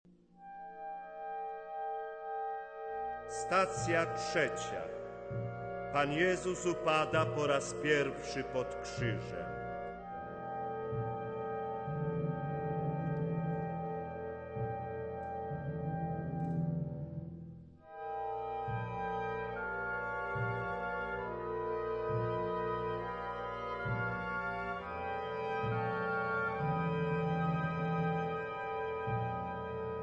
sopran/soprano
baryton/baritone